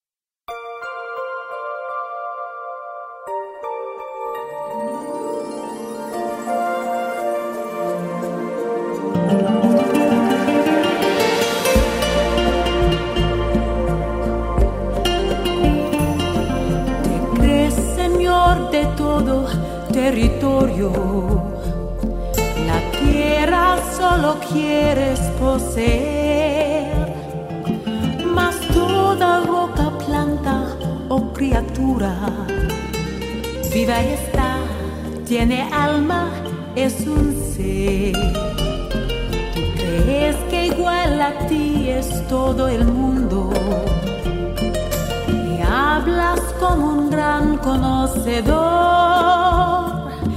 cinematografica.mp3